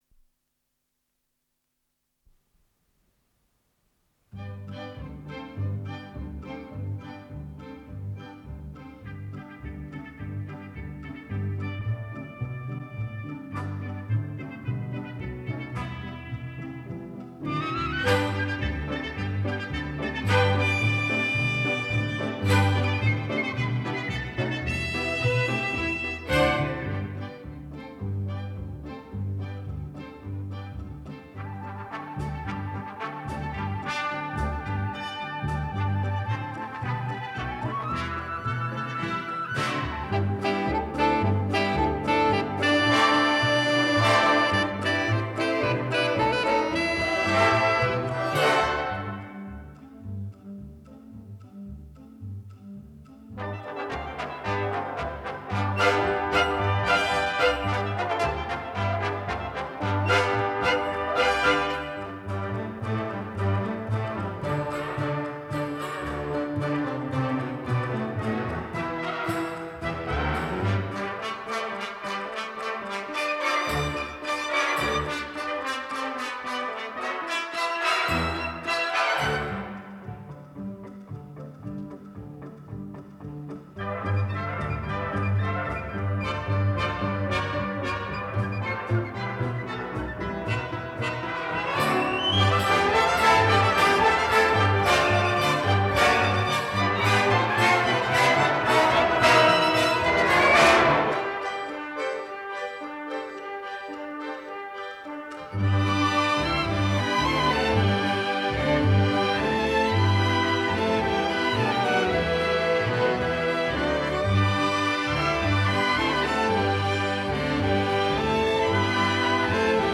музыкальная картинка
Моно звучание